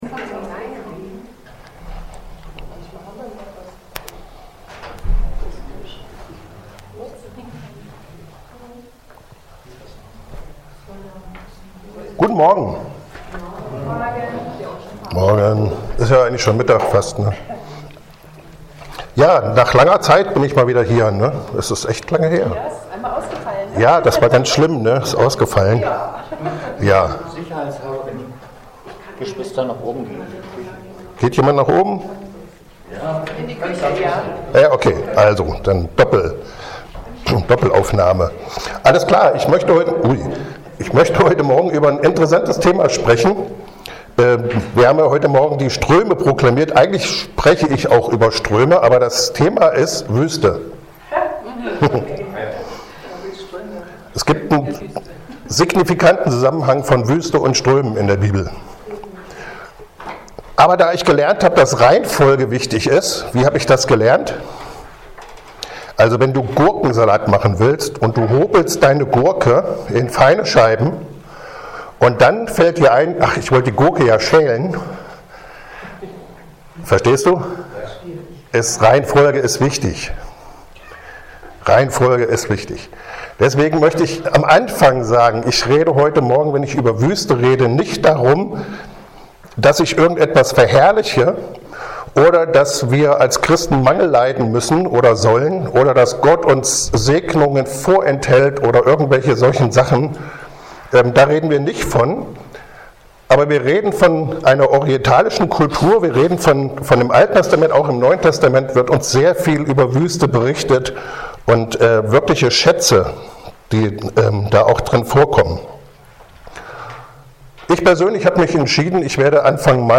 Externe Prediger